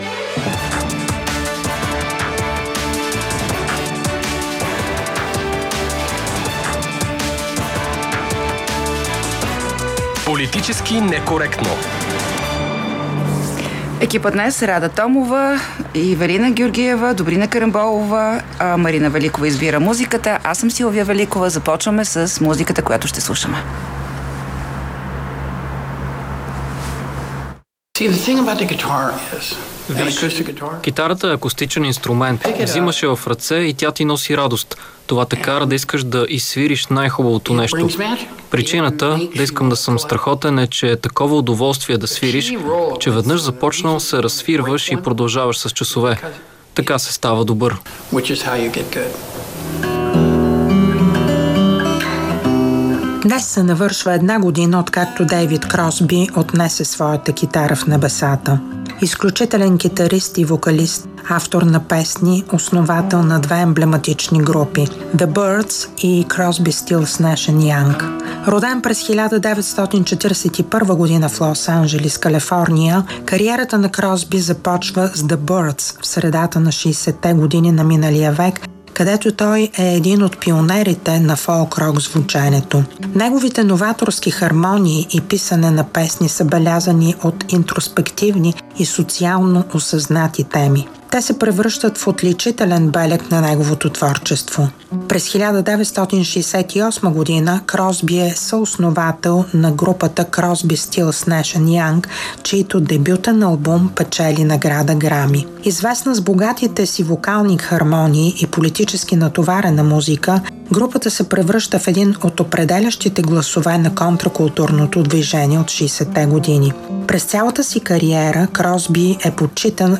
Коментираме със съдия Мирослава Тодорова от Съюза на съдиите в България.